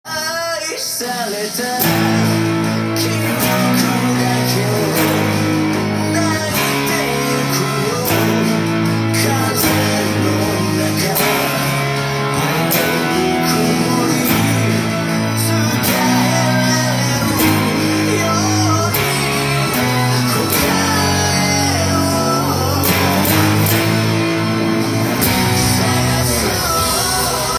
音源に合わせて譜面通り弾いてみました
パワーコードのみで記載しています。
リズムは非常にカンタンで２分音符を主体に
４分音符と８分音符だけの構成です。